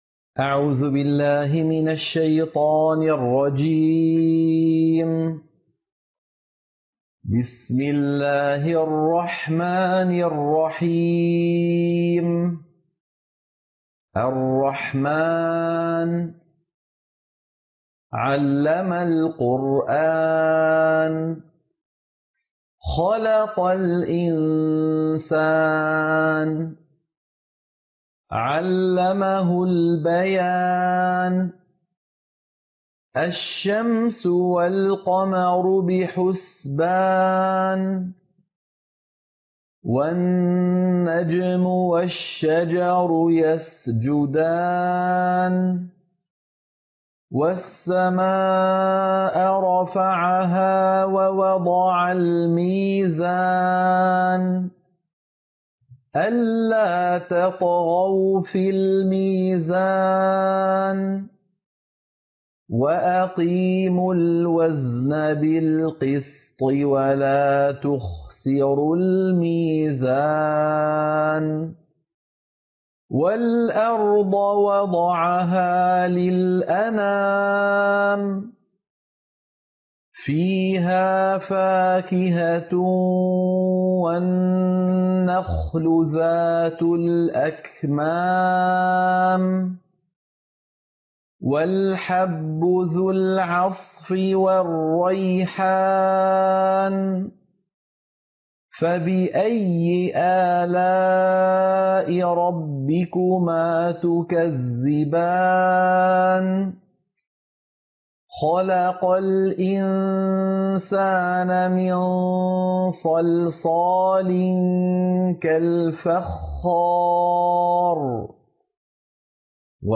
سورة الرحمن - القراءة المنهجية